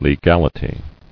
[le·gal·i·ty]